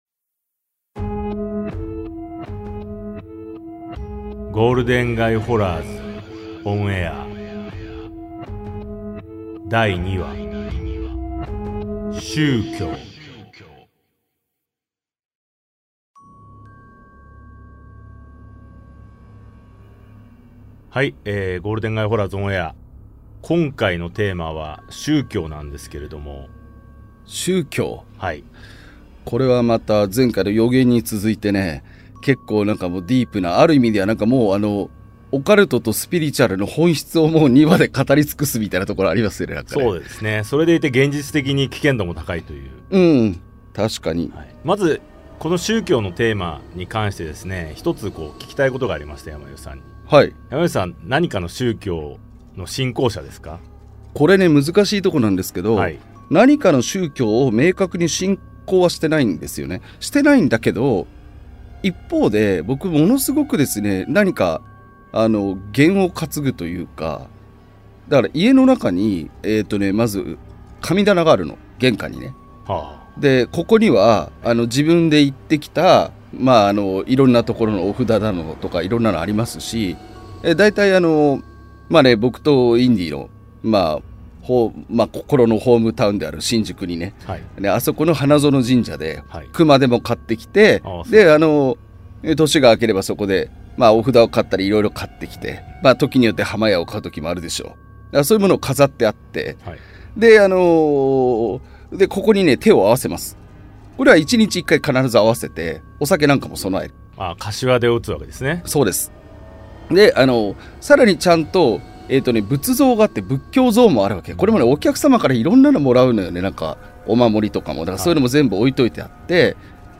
対談形式のホラー番組